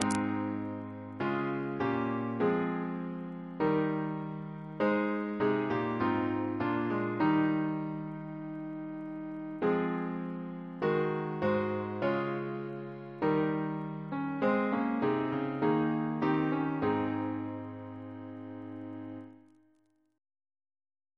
Double chant in A♭ Composer: Sir John Goss (1800-1880), Composer to the Chapel Royal, Organist of St. Paul's Cathedral Reference psalters: ACB: 317; ACP: 94